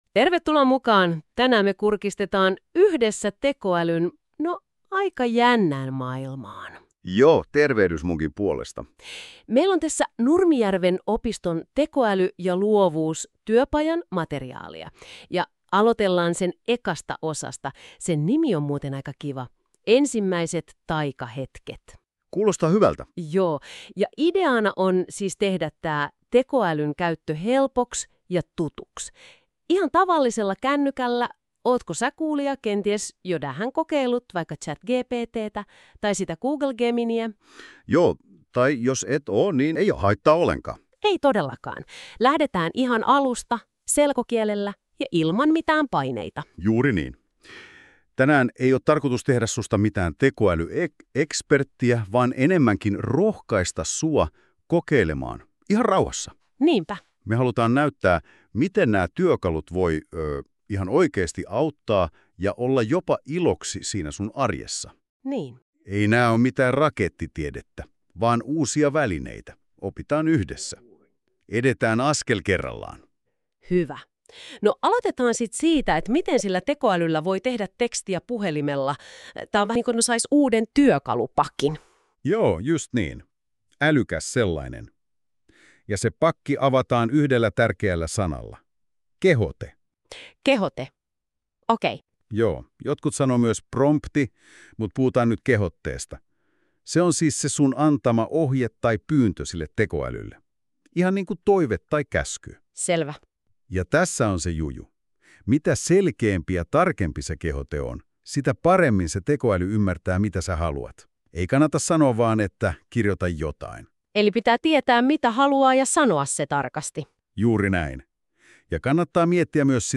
Opimme myös kolme helppoa periaatetta, joilla saat parempia vastauksia tekoälyltä! Tämän jakson sisältö on tehty Geminin ja ChatGPT:n avulla, ja podcast on luotu NotebookLM:llä lokakuussa 2025.